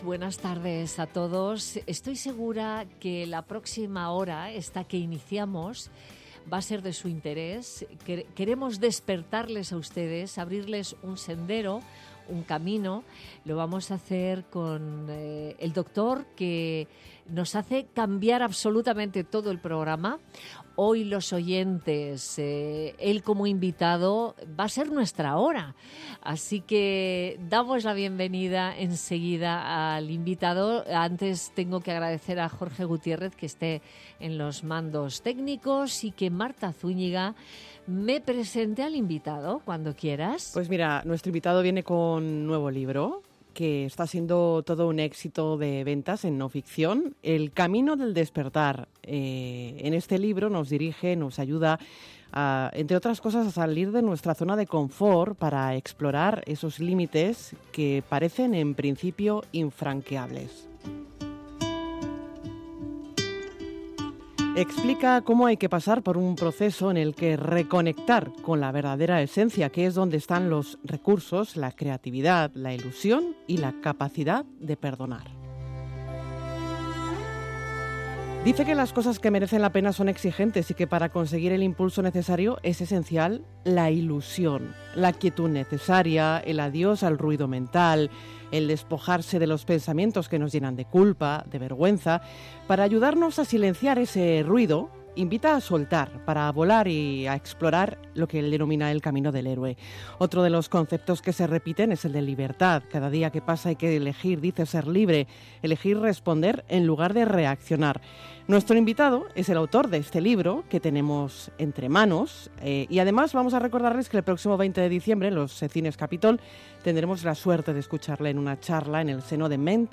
El doctor, conferenciante y escritor, Mario Alonso Puig, ha pasado por los micrófonos de Madrid Directo con Nieves Herrero para presentar su nuevo libro El camino del despertar editado por Espasa. Una obra inspiradora para ayudar a las personas a transformarse en quienes siempre han querido ser.